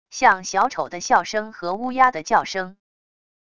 像小丑的笑声和乌鸦的叫声wav音频